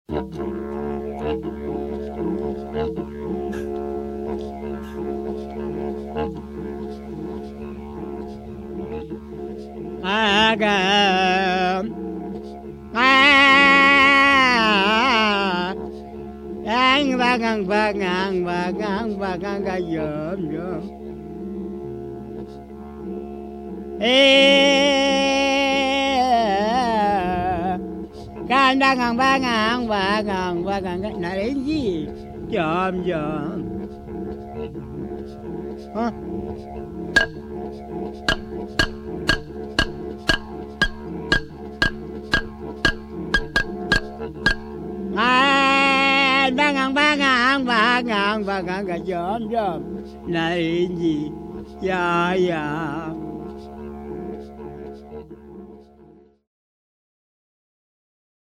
'Green Frog'. Arnhem Land; Northern Australia, Wangga song.
voice & clapsticks
didjeridu.
Didjeridu.mp3